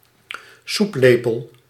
Ääntäminen
Ääntäminen US : IPA : ['teɪ.bl.spuːn]